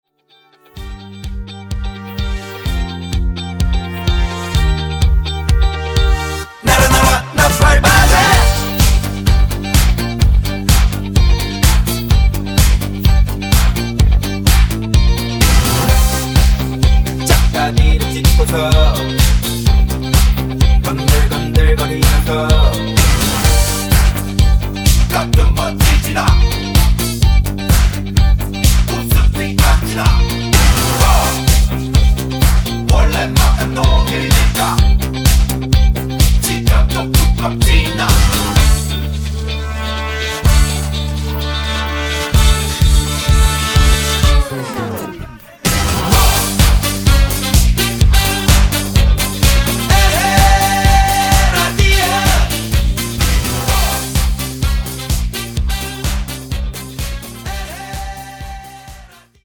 음정 원키 3:43
장르 가요 구분 Voice MR